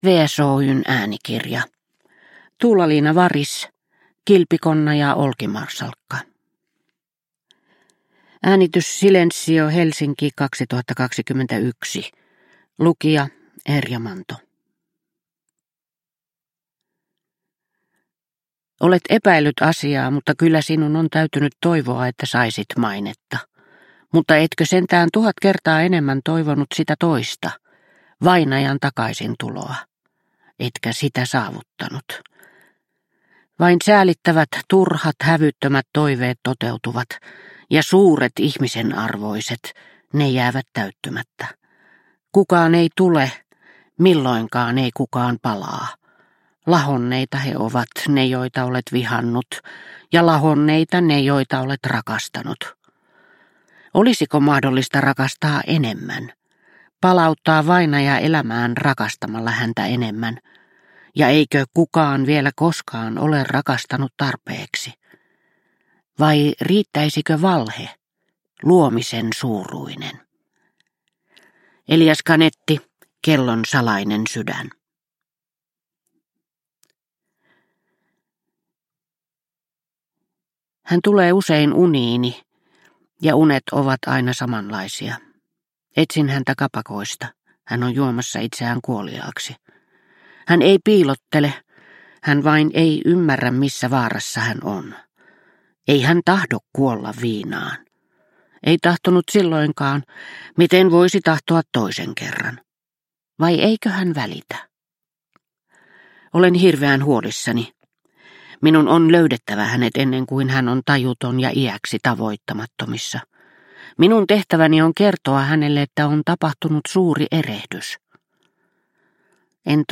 Kilpikonna ja olkimarsalkka – Ljudbok – Laddas ner